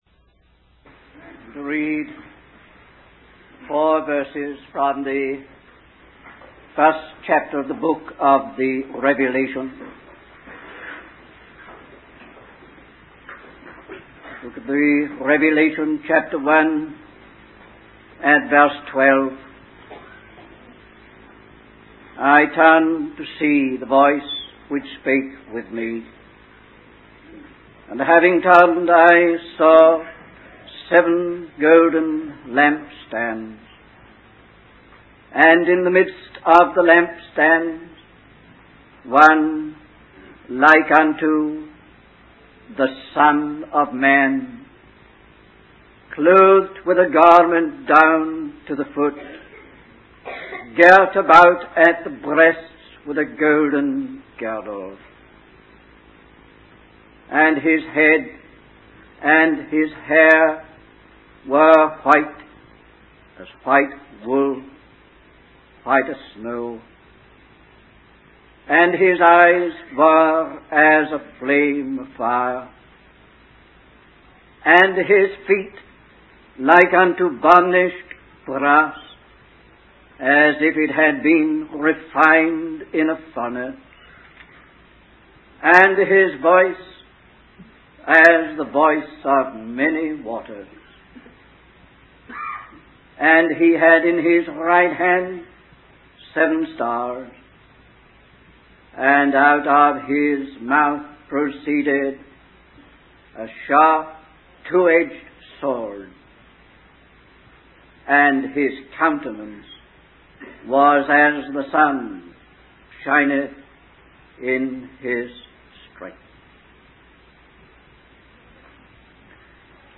In this sermon, the speaker focuses on the first chapter of the book of Revelation. They discuss how this book reveals the processes and progress leading to the final establishment of God's government.